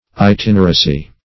Search Result for " itineracy" : The Collaborative International Dictionary of English v.0.48: Itineracy \I*tin"er*a*cy\ ([-i]*t[i^]n"[~e]r*[.a]*s[y^]), n. The act or practice of itinerating; itinerancy.
itineracy.mp3